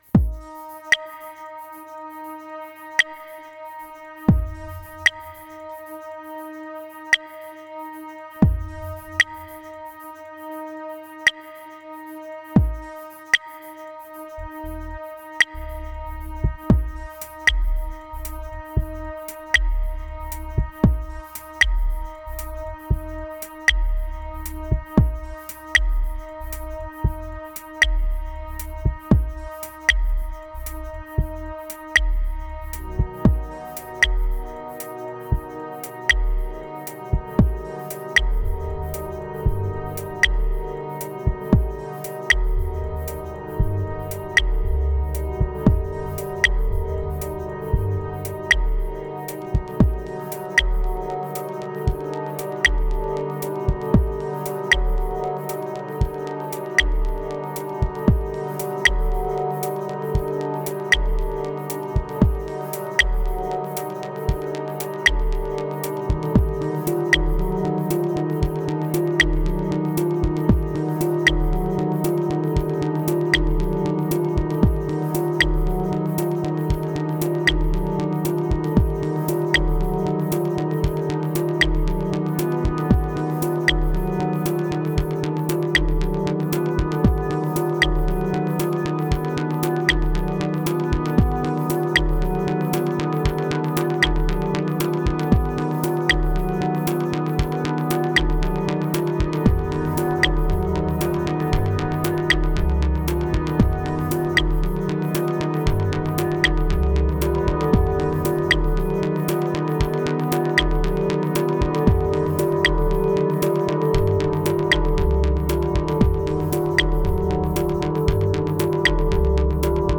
Prepare your subwoofer or your deep headz.